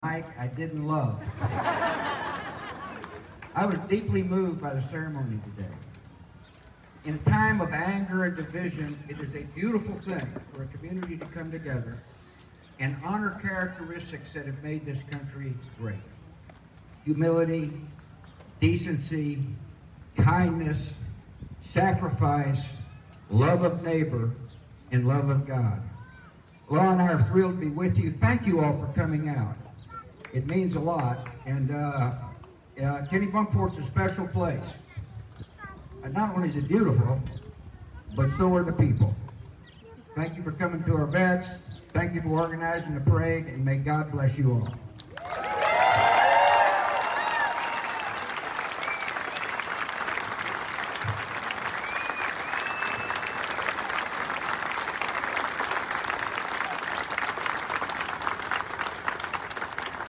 George W. Bush making his Memorial Day remarks just now at our annual parade.